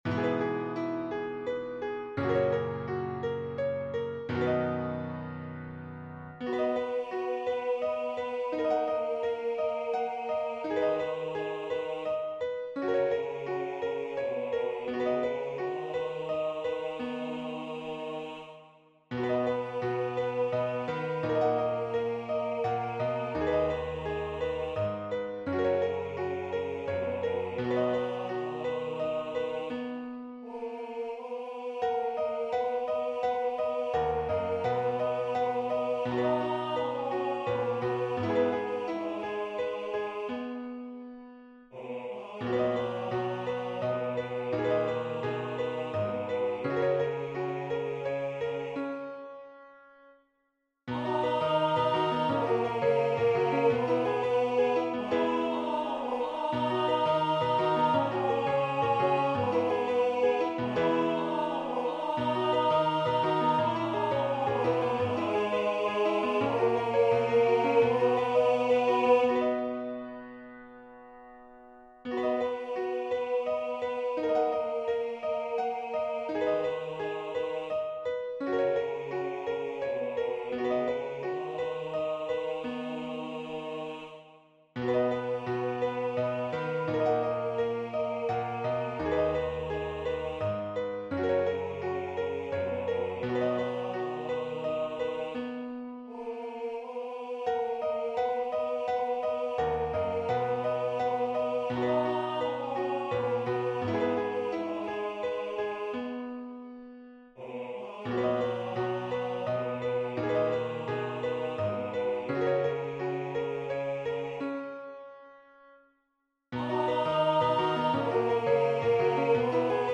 Vocal Solo
Guitar Chords Available
Medium Voice/Low Voice
EFY style/Contemporary